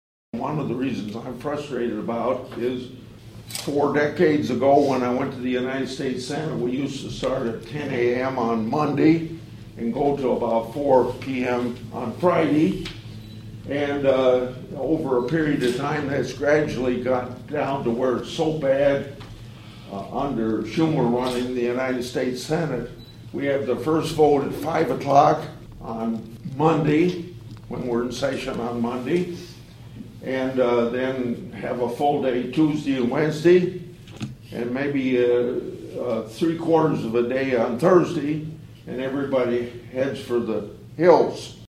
(Atlantic) Senator Chuck Grassley held a town hall meeting at the Iowa Western Community College Cass County campus in Atlantic this (Tuesday) morning.